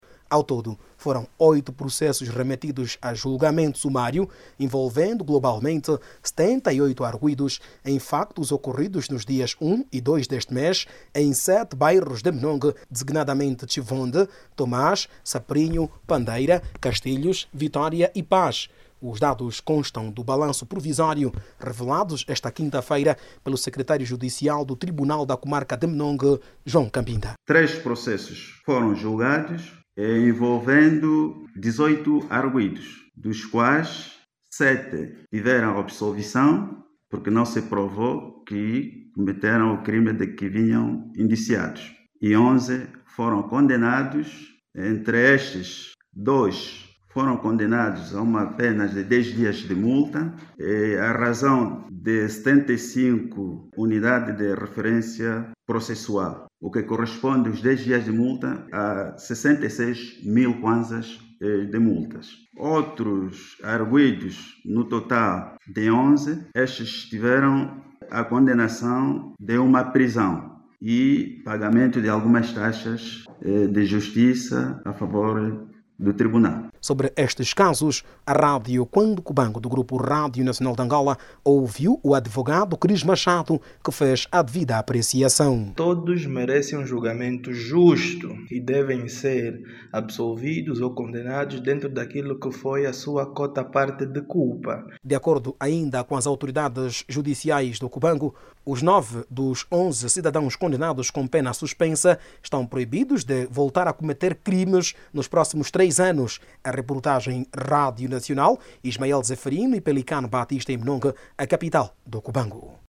Os factos ocorreram nos dias 1 e 2 deste mês, na cidade de Menongue, província do Cubango. Jornalista